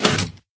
sounds / tile / piston / out.ogg